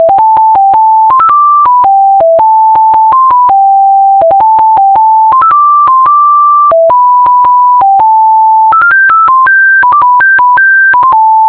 Nokia Composer (Tempo=112)